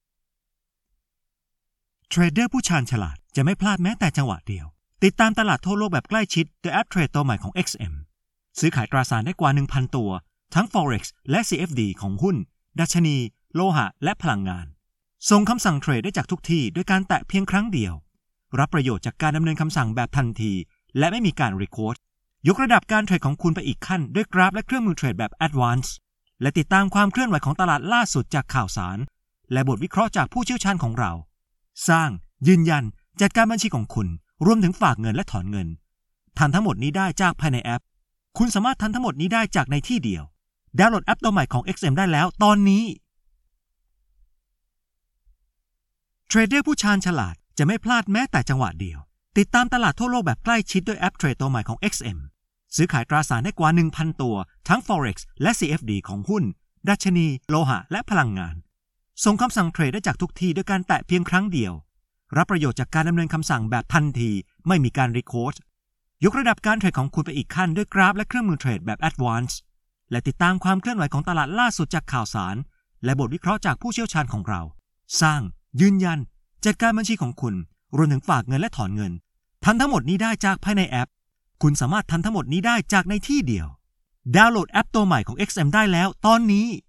Male
Thai (native)
Voice reels
Microphone: Sony
Audio equipment: Scarlett Solo